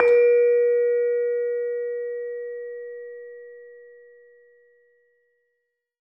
LAMEL A#3 -R.wav